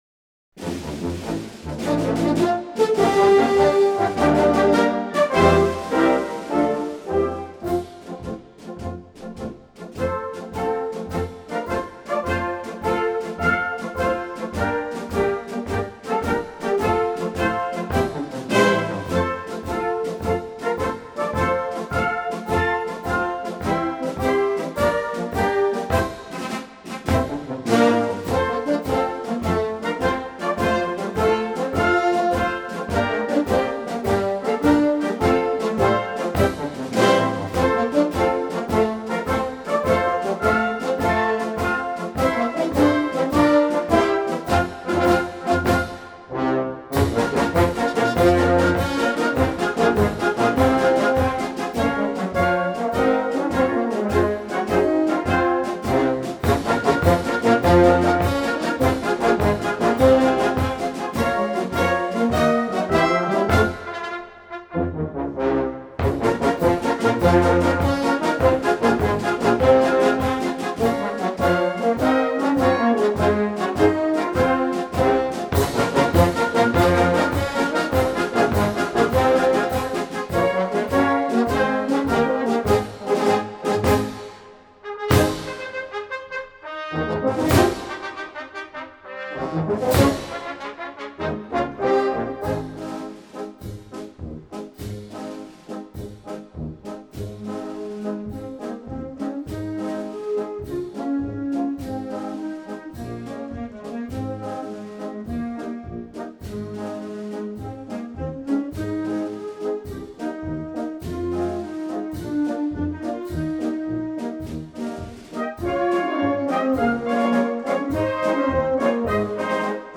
für großes Blasorchester…
Blasmusik
Konzertmarsch